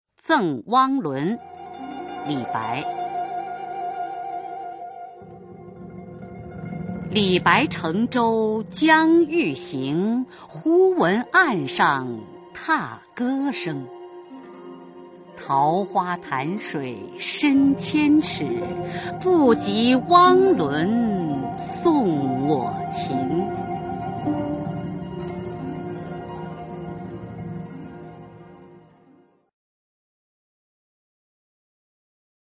[隋唐诗词诵读]李白-赠汪伦（女） 古诗文诵读